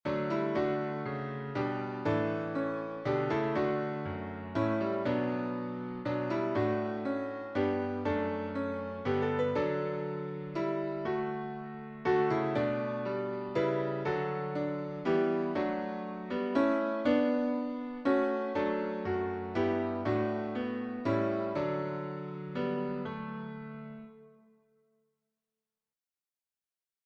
Irish melody